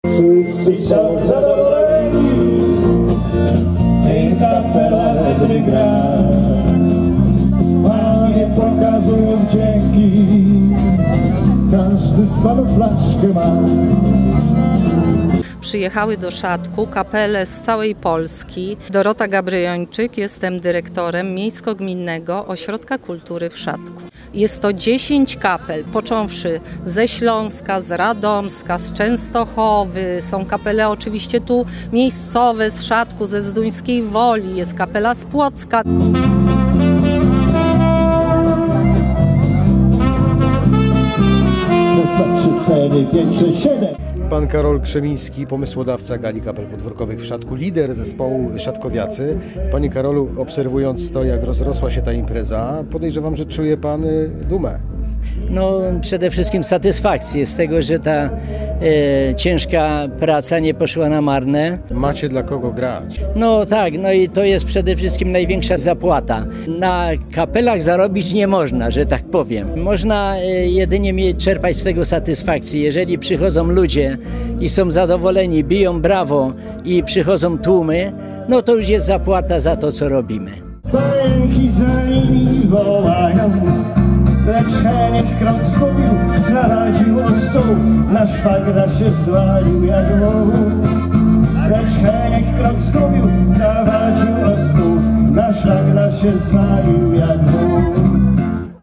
Szadek koło Zduńskiej Woli rozbrzmiewał dziś (3 lipca) muzyką podwórkową. Na zorganizowaną już po raz 18. Galę Kapel przyjechały zespoły z różnych zakątków Polski.